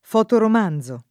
fotoromanzo